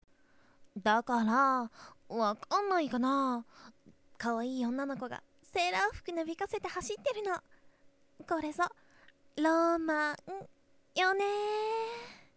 ネット声優に50のお題はこちらお借りしました
11.ロマン なんだか変態っぽくなってしまいました（笑）